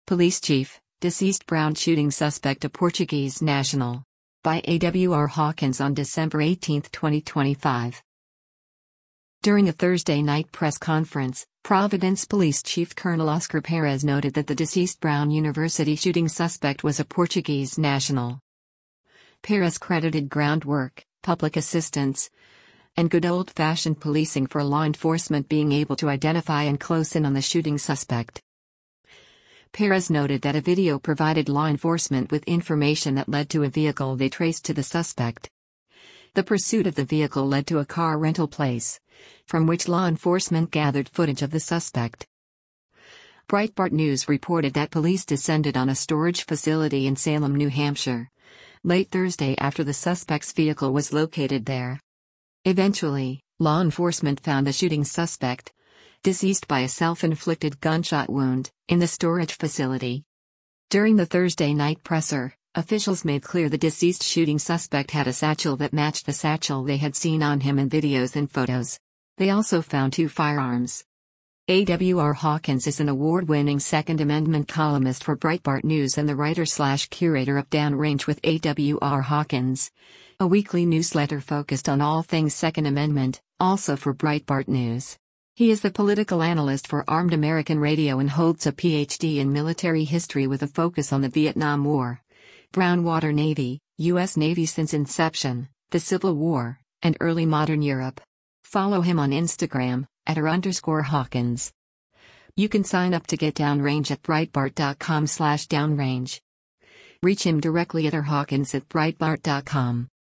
During a Thursday night press conference, Providence police chief Colonel Oscar Perez noted that the deceased Brown University shooting suspect was “a Portuguese national.”